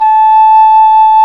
Index of /90_sSampleCDs/Roland LCDP04 Orchestral Winds/CMB_Wind Sects 1/CMB_Wind Sect 7
WND ENGHRN0H.wav